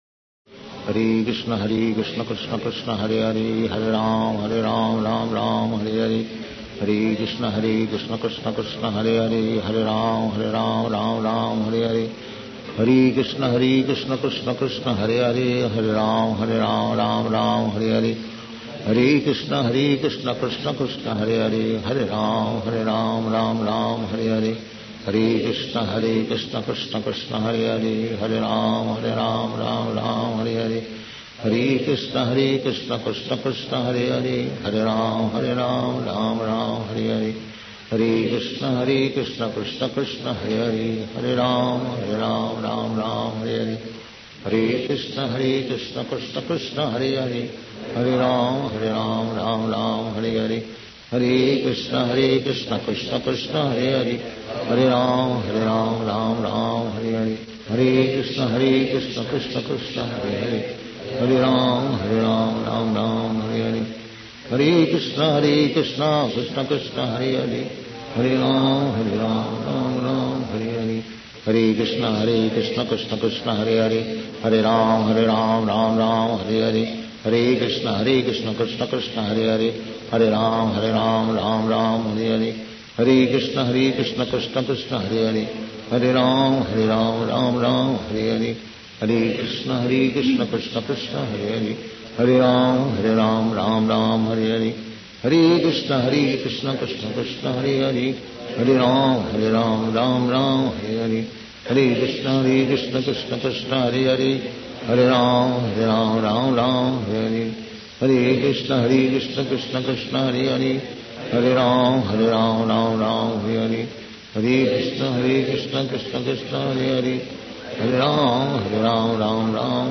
Шрила Прабхупада - Джапа 01